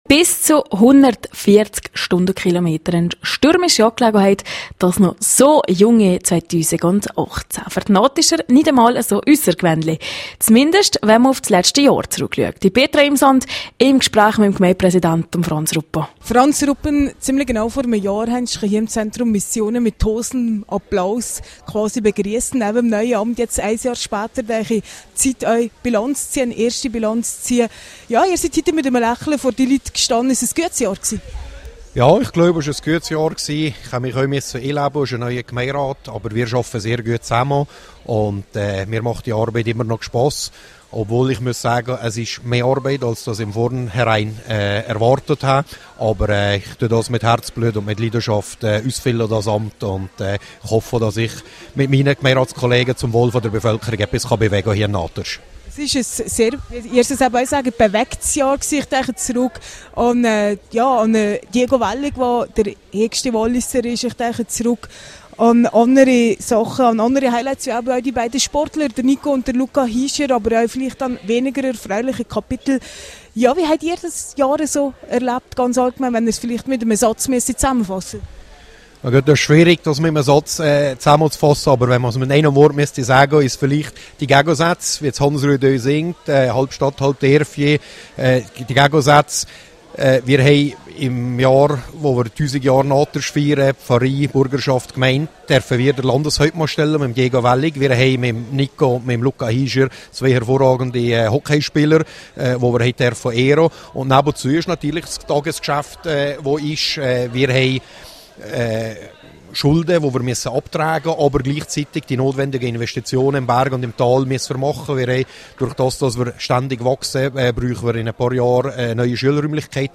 Im Anschluss liessen alle Anwesenden den Abend gemütlich bei Polenta und Ragout ausklingen./ip Gemeindepräsident Franz Ruppen resümiert das Jahr 2017 (Quelle: rro)